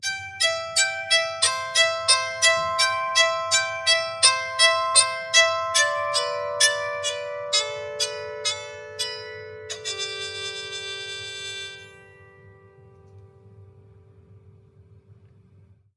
Soprano Psaltery melody sequence - Am - 126.mp3
Original creative-commons licensed sounds for DJ's and music producers, recorded with high quality studio microphones.
Channels Stereo
soprano_psaltery_melody_sequence_-_am_-_126_tcs.ogg